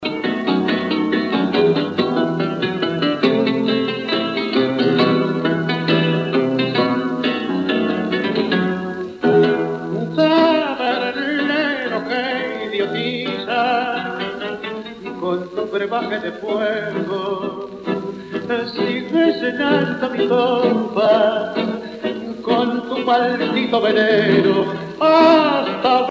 danse : tango (Argentine, Uruguay)
Pièce musicale éditée